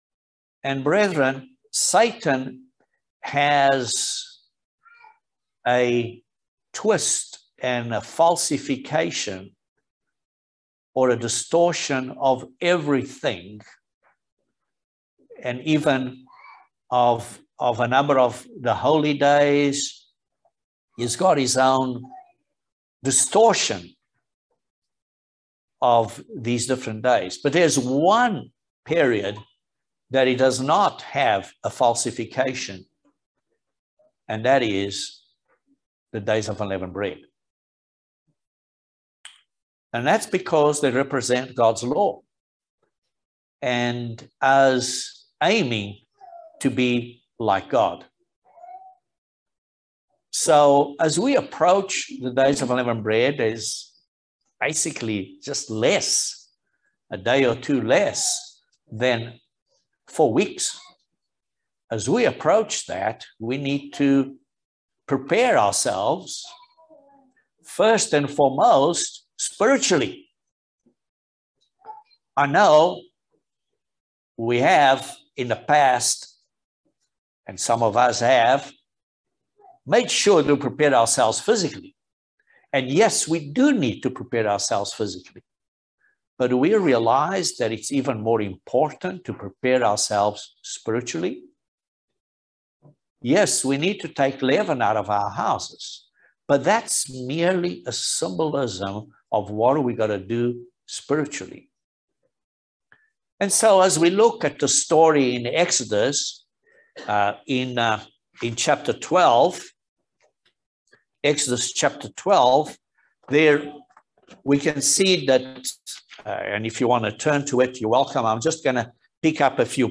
Join us for this eye opening video sermon about the Spiritual Benefits of God's Days of Unleavened Bread festival. These 7 days offer a glimpse into God's Holy Day plan.